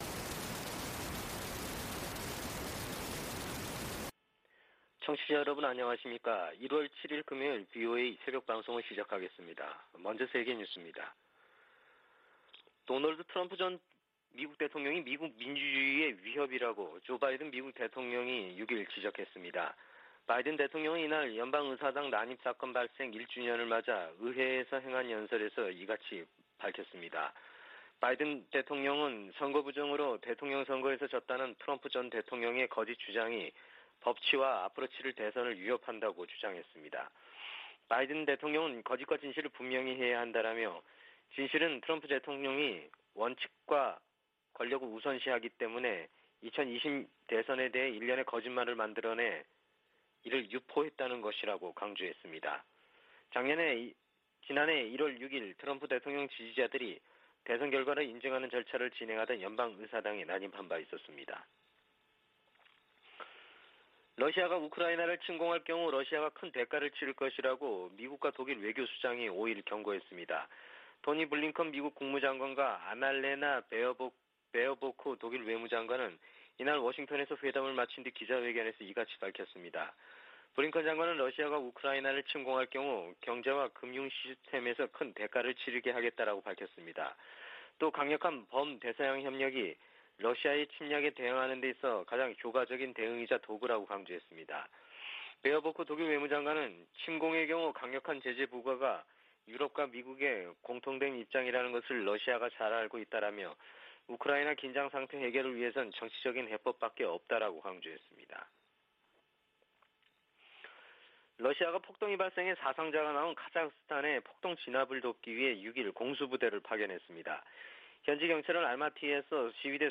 VOA 한국어 '출발 뉴스 쇼', 2021년 1월 7일 방송입니다. 북한은 5일 쏜 단거리 발사체가 극초음속 미사일이었다고 밝혔습니다. 토니 블링컨 미국 국무장관이 북한의 새해 첫 미사일 도발을 규탄했습니다. 1월 안보리 의장국인 노르웨이는 북한의 대량살상무기와 탄도미사일 개발에 우려를 표시했습니다.